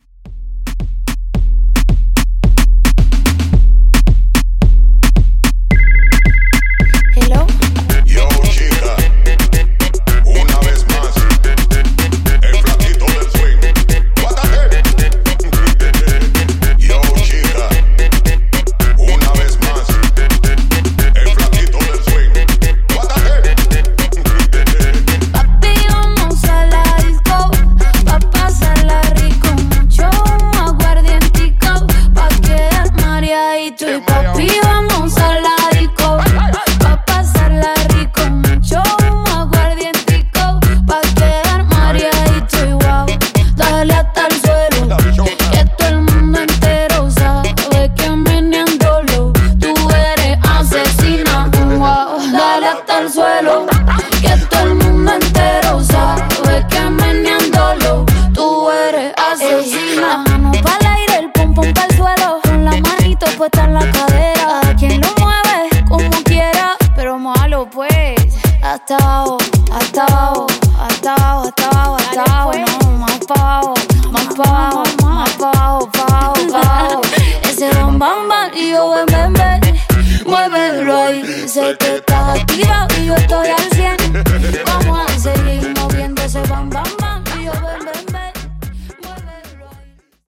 In-Outro Moombah)Date Added